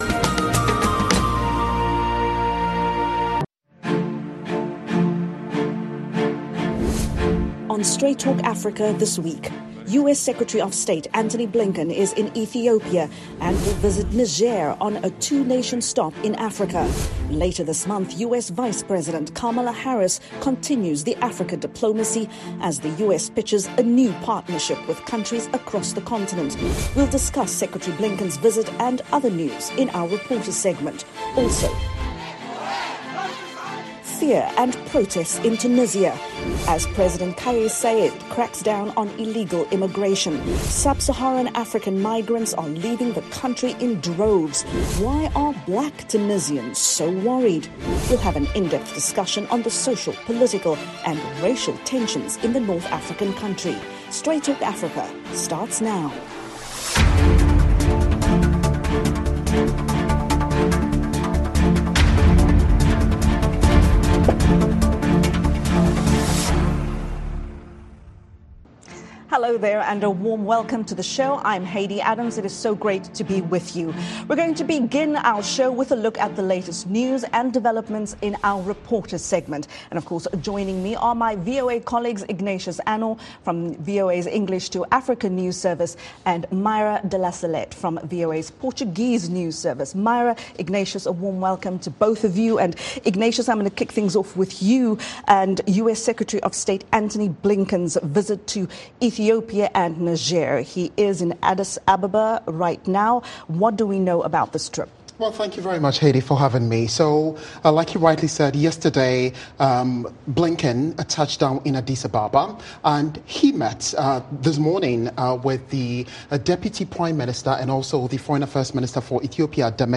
Later this month, U.S. Vice President Kamala Harris will tour Ghana, Tanzania and Zambia. Also, we’ll have an in-depth discussion on why Black migrants are fleeing Tunisia after controversial comments by President Kais Saied.